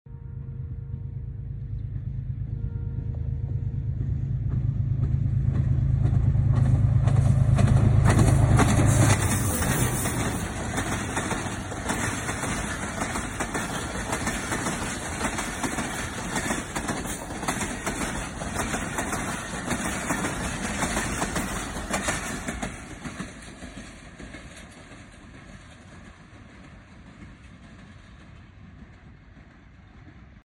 Mitti Express Crossing Vehari || Sound Effects Free Download
Mitti express crossing Vehari ||